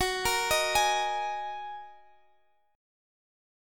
Listen to Gb6add9 strummed